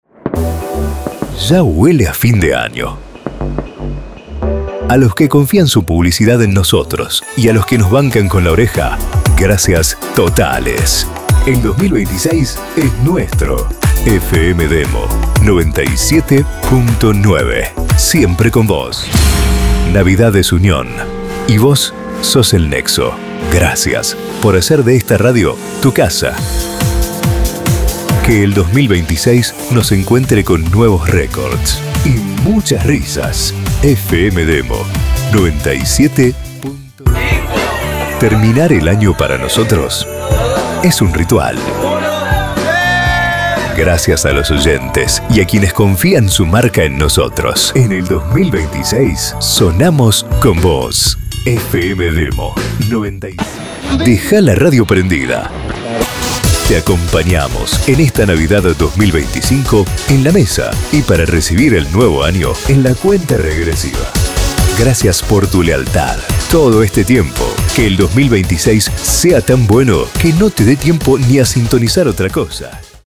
Mensajes especiales dirigidos a los oyentes y anunciantes que acompañaron a la emisora durante todo el año. Empáticos, cercanos, emotivos y alentadores.
✅ Edición con fondos musicales y efectos de alta calidad.
✅ Locución Amena, Cercana y alentadora.